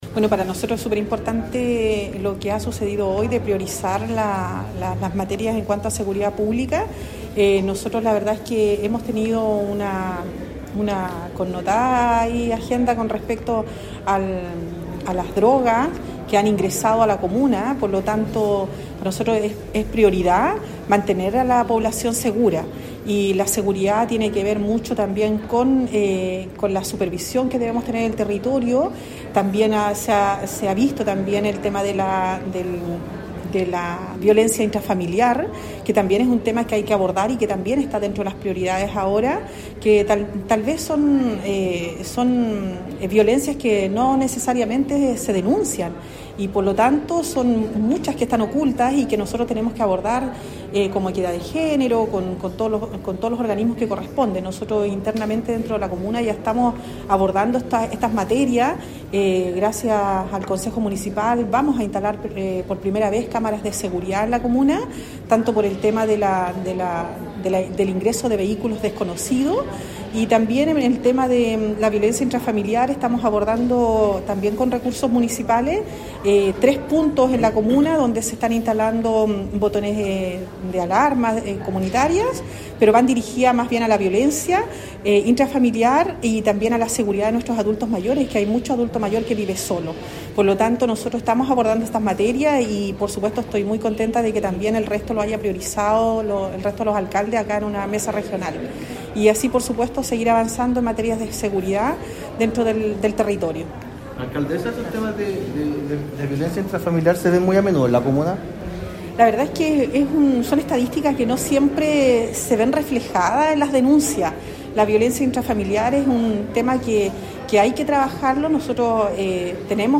Mientras que la Alcalde de Río Hurtado, Carmen Juana Olivares, manifestó que
Carmen-Juan-Olivares-Alcaldesa-de-Rio-Hurtado.mp3